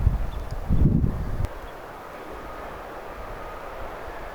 isokäpylintuja?
olisiko_isokapylintuja.mp3